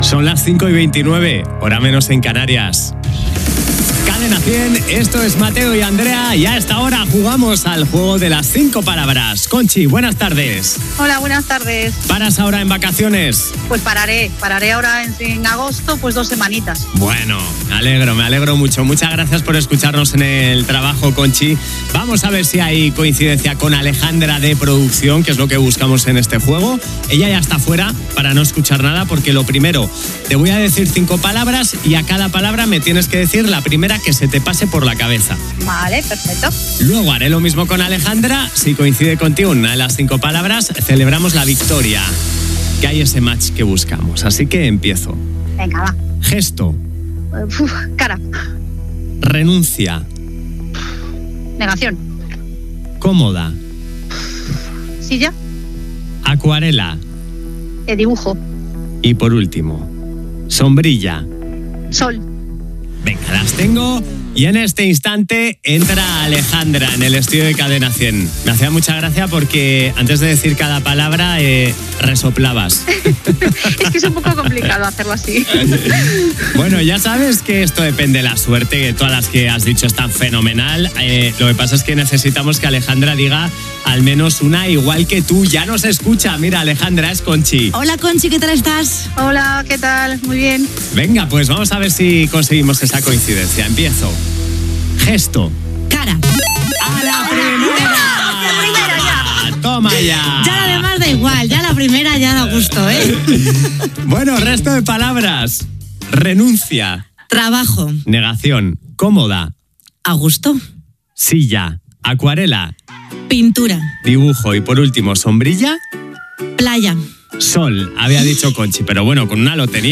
Hora, concurs amb els oients amb el joc de les cinc paraules.